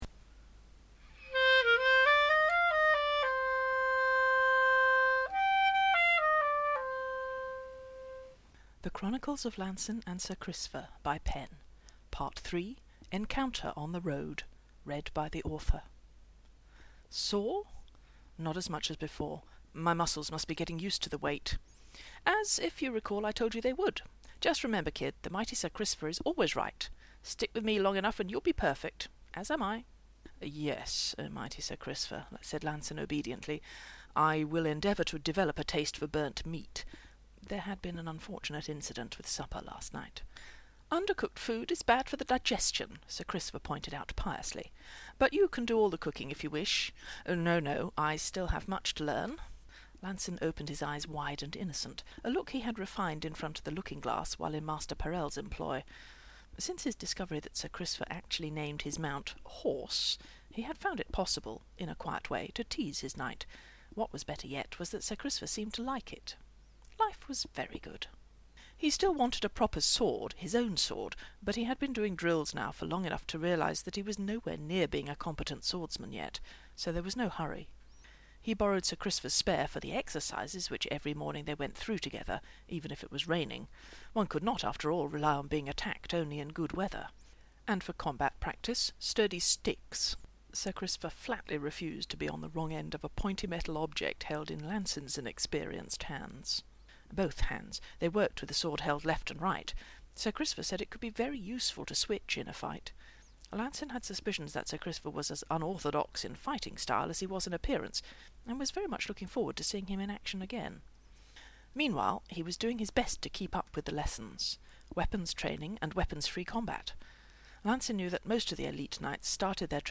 This story is available as podfic (mp3):